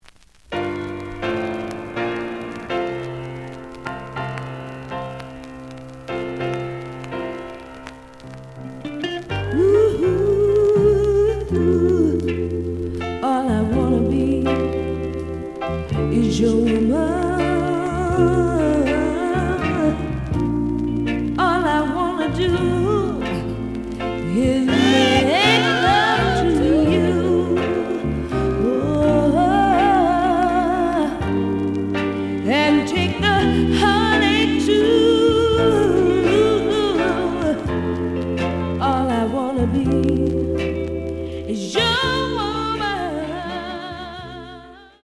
The audio sample is recorded from the actual item.
●Genre: Soul, 70's Soul
Slight noise on both sides.